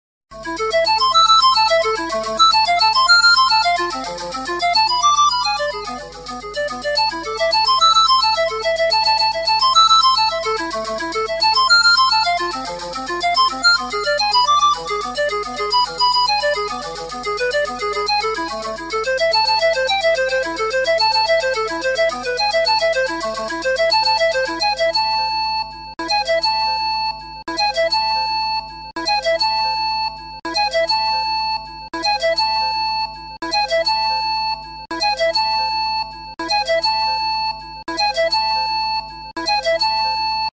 • Качество: 192, Stereo
громкие
Electronic
без слов
звонкие
Стандартная звонкая мелодия на телефоне